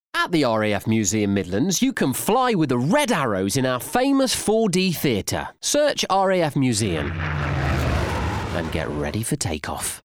The sonic ident reflected the history and longevity of the RAF – by gradually morphing the sound of a spitfire-esque plane into that of a modern fighter jet.